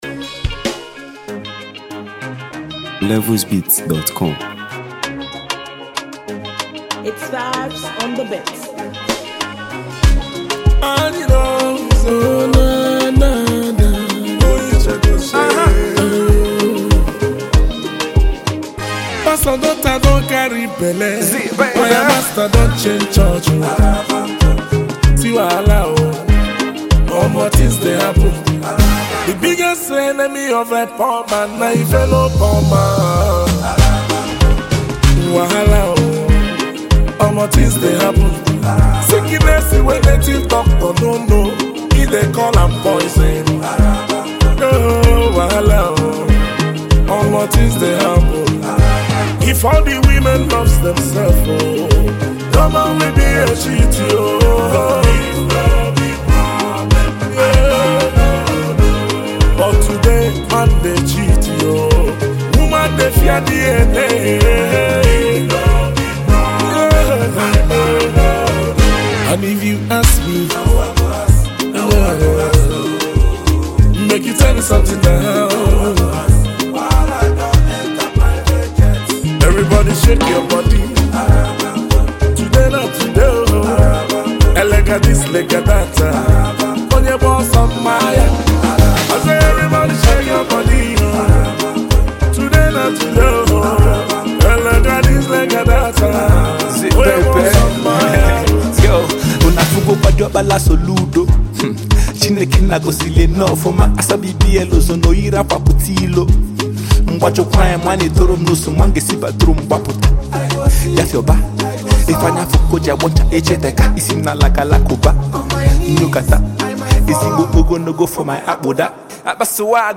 Nigeria Music 2025 3:07
energetic and culturally rich record
indigenous rap powerhouse
whose hard-hitting verses add a dynamic edge to the song.